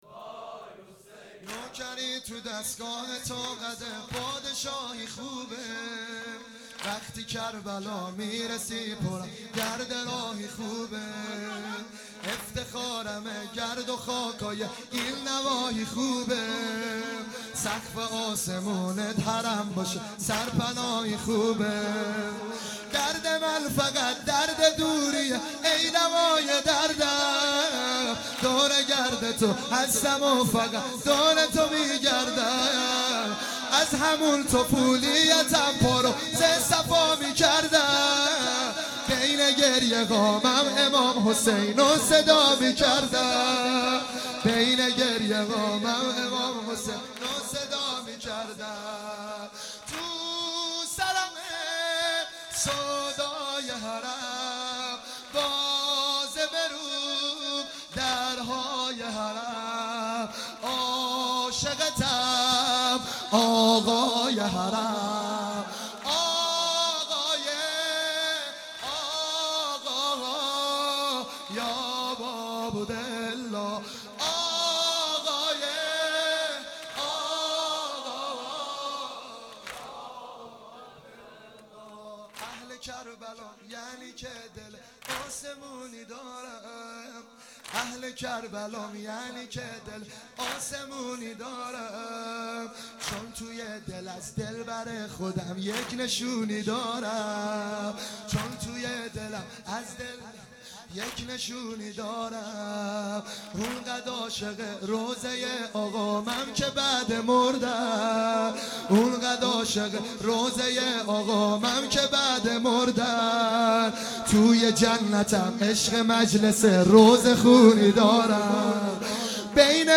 سنگین | نوکری تو دستگاه تو قد پادشاهی خوبه
مداحی
در شب ششم محرم الحرام 1439